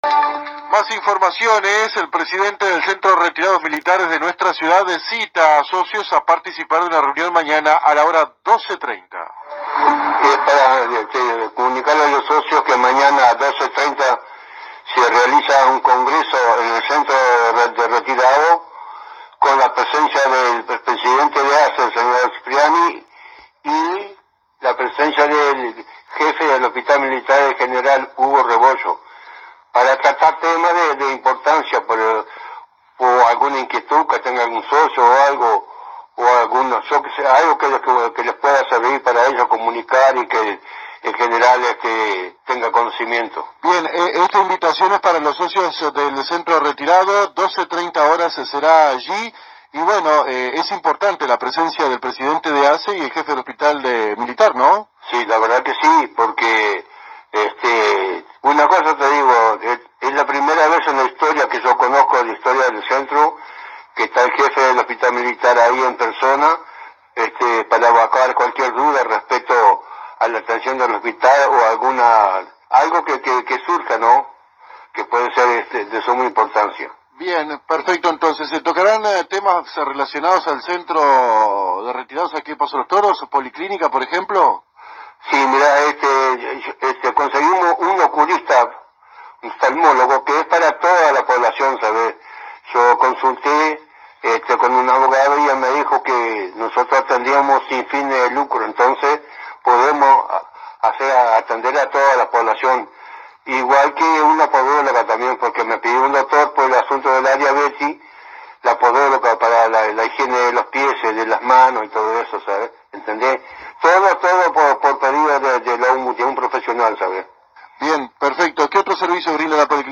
Refirió el entrevistado que en la policlínica que funciona en su sede, calle Etcheverry 689, se prestan diferentes servicios a la población en general, tales como odontología, podología, ginecología y medicina general, y que además cuentan con una ambulancia para la atención de sus socios.